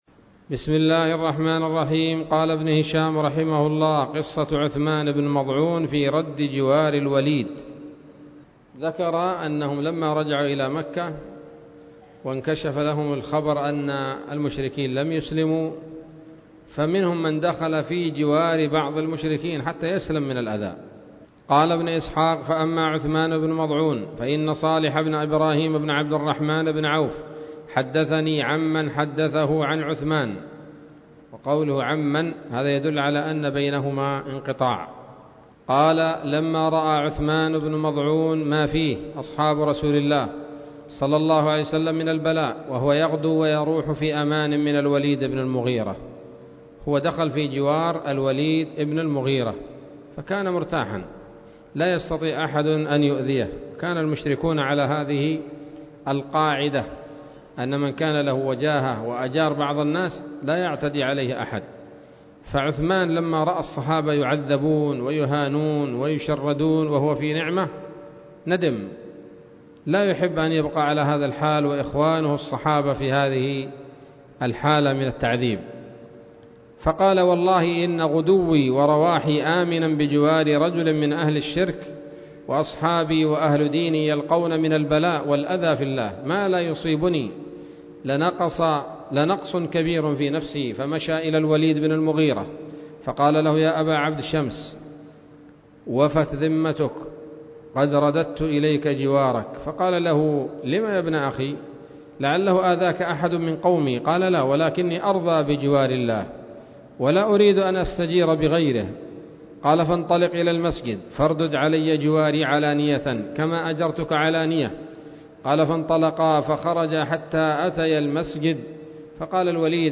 الدرس الأربعون من التعليق على كتاب السيرة النبوية لابن هشام